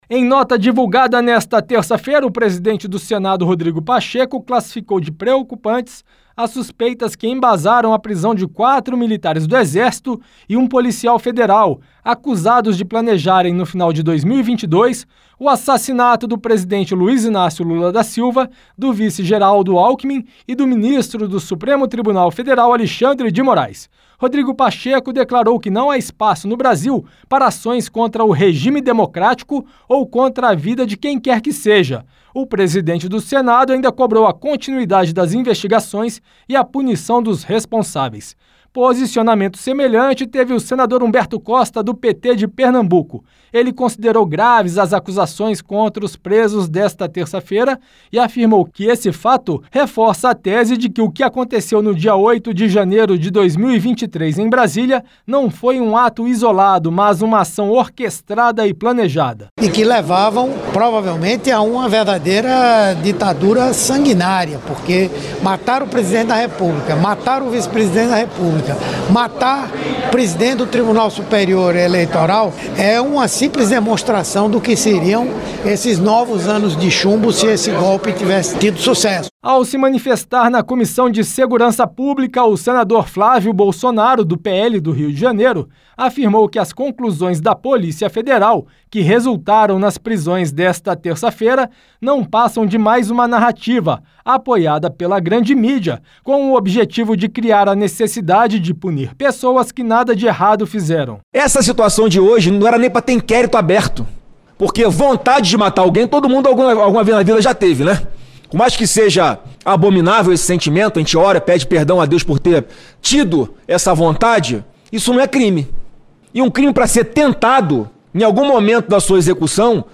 Pronunciamento